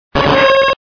contributions)Televersement cris 4G.